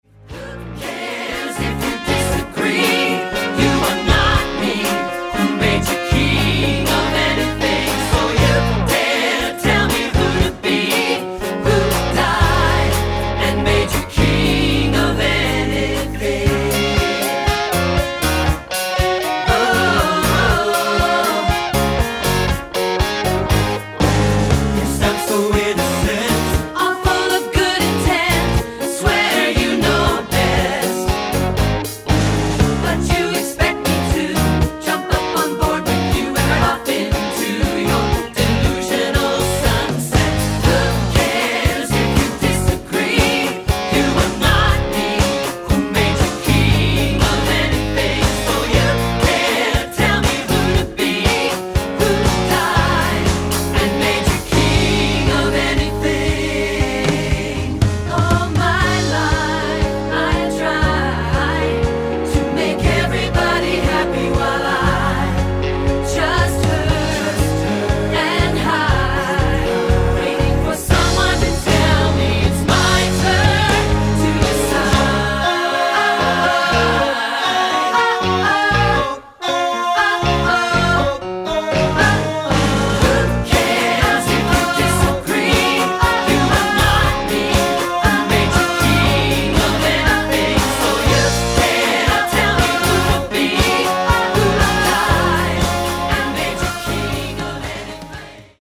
Choral Early 2000's Pop
A playful pop tune with feisty lyrics
SATB